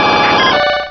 Cri de Poissoroy dans Pokémon Rubis et Saphir.
Cri_0119_RS.ogg